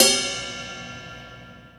• Ride Sound E Key 07.wav
Royality free ride sound tuned to the E note. Loudest frequency: 5030Hz
ride-sound-e-key-07-Uq5.wav